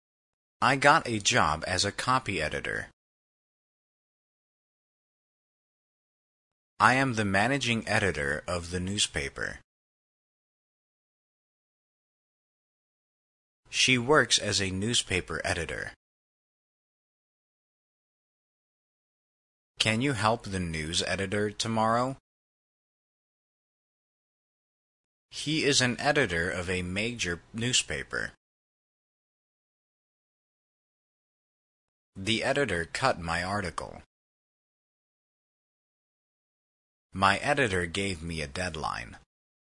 editor-pause.mp3